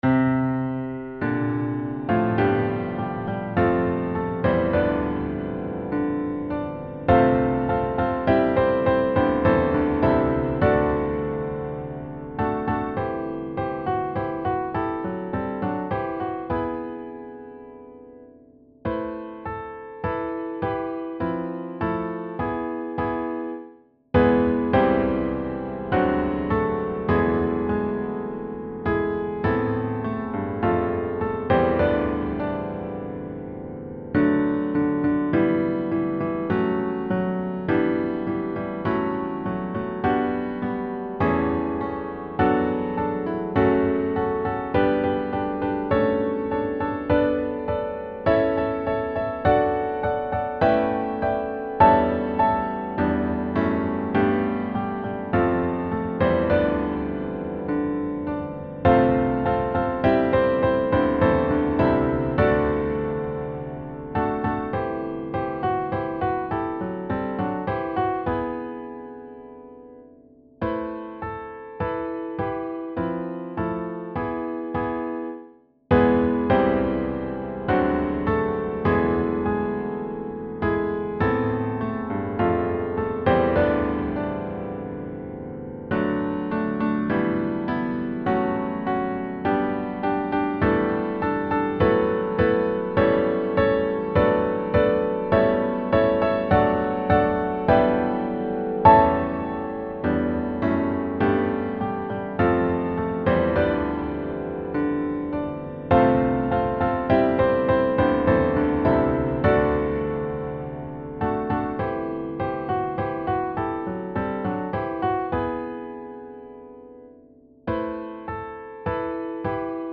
• Music Type: Choral
• Voicing: SATB
• Accompaniment: Organ